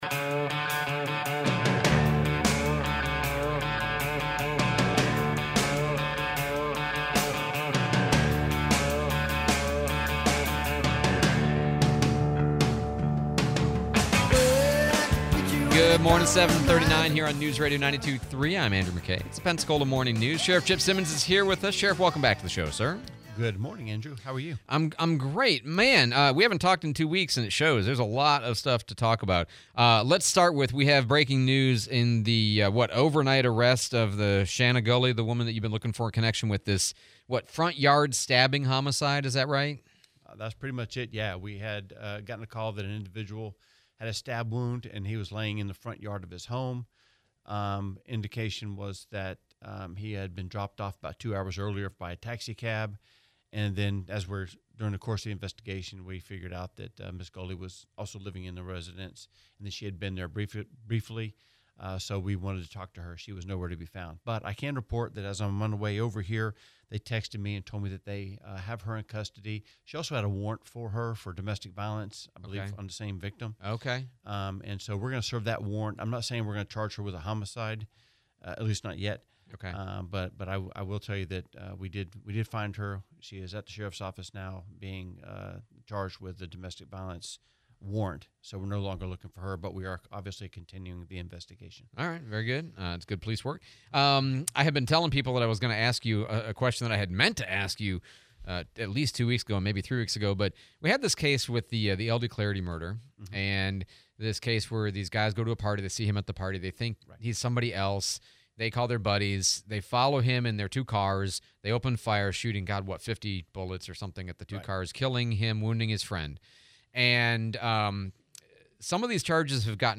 08/19/25 Sheriff Chip Simmons interview